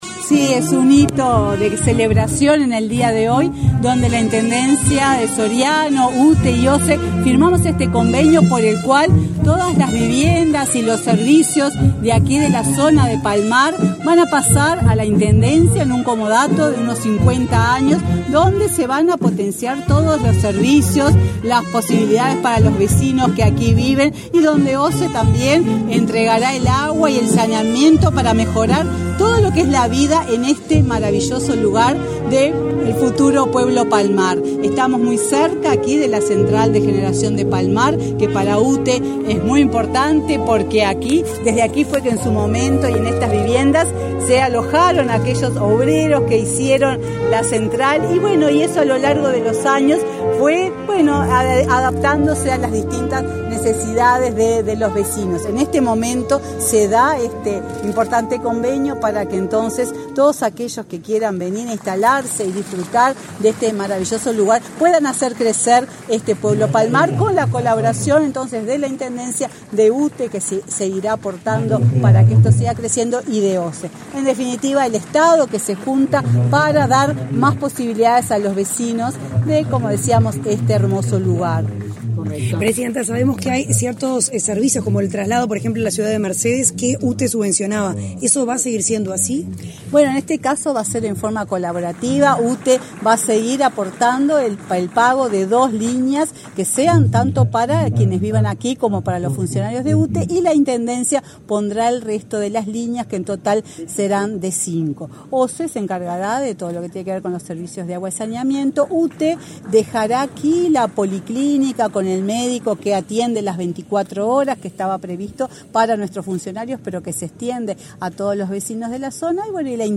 Declaraciones a la prensa de la presidenta de UTE, Silvia Emaldi
Declaraciones a la prensa de la presidenta de UTE, Silvia Emaldi 24/03/2023 Compartir Facebook X Copiar enlace WhatsApp LinkedIn Tras participar en la firma de convenio entre UTE, OSE e Intendencia de Soriano, este 24 de marzo, mediante el cual se efectuarán obras viales en el pueblo Palmar, Silvia Emaldi realizó declaraciones a la prensa.